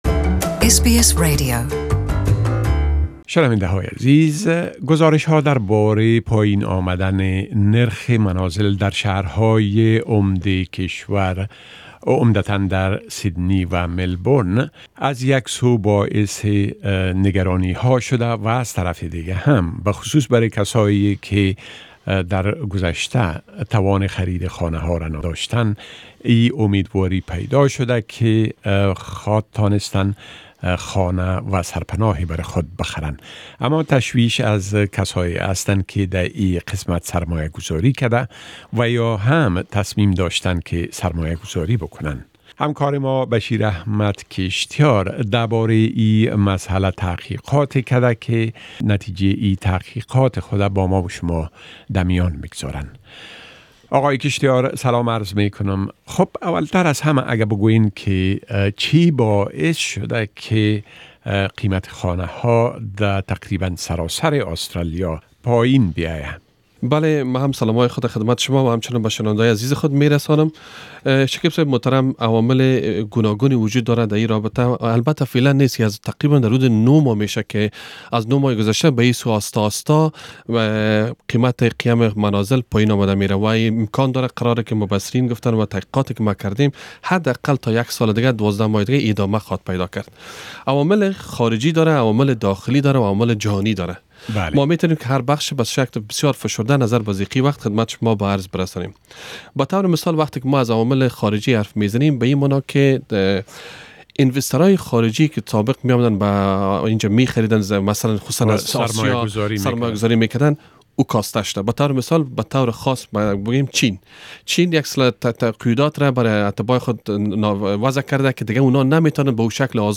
A discussion about the decline of house prices in Australia